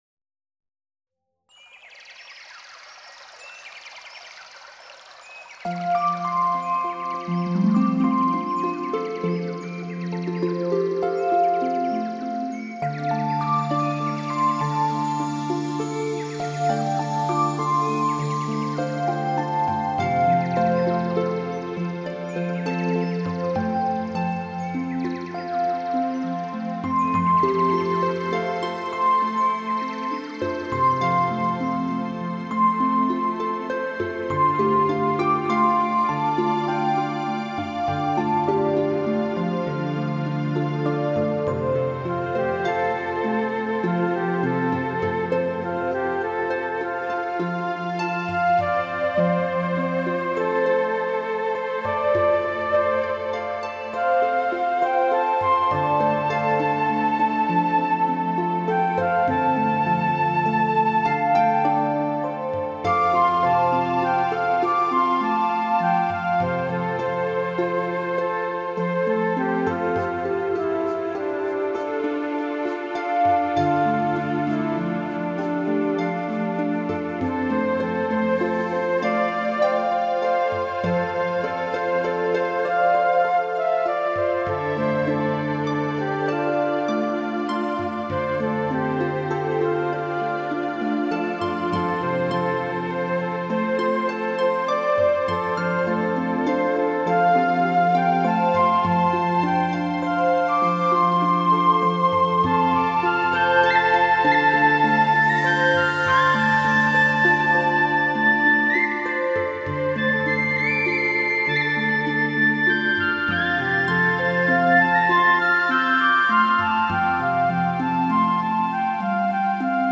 舒缓压力、放松肌肉与神经